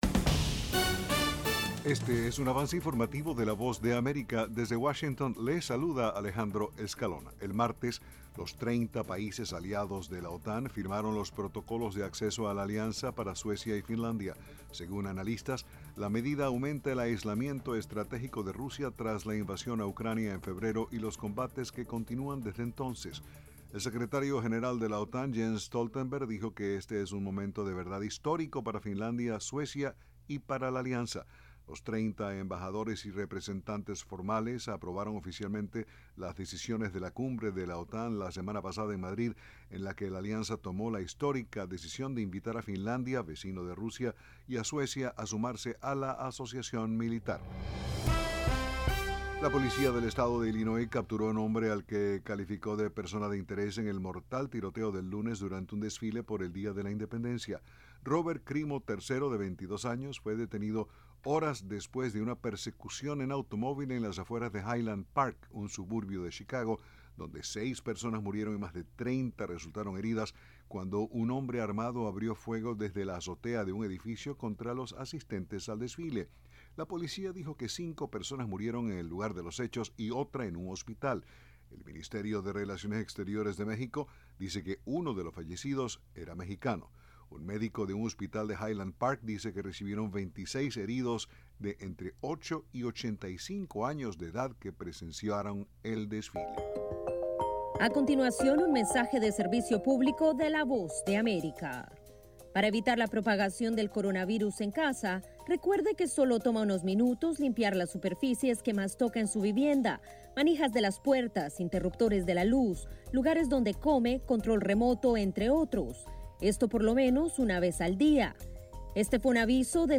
Este es un avance informativo presentado por la Voz de América desde Washington.